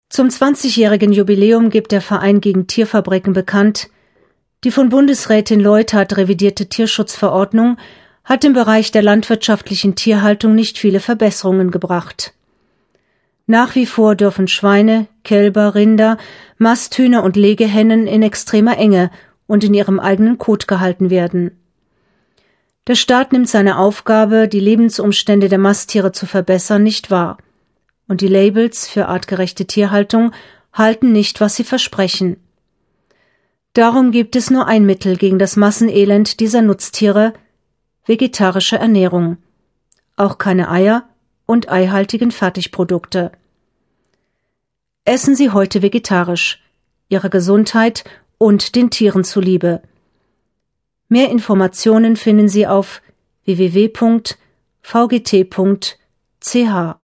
Radio-Spot zum 20-jährigen Jubiläum des VgT